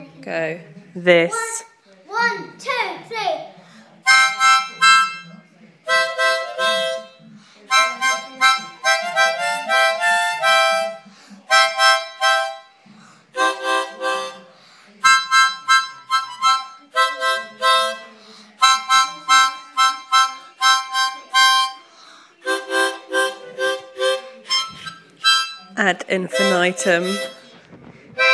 Glum harmonica boo